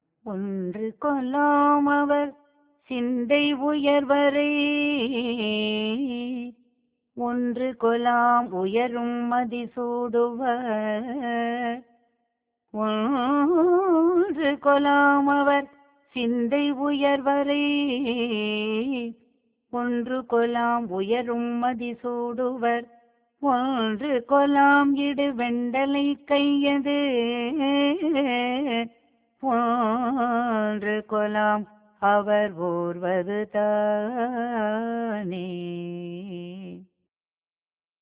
‘இந்தளப்’ பண்ணில் உருக்கமாகப் பாடினார்.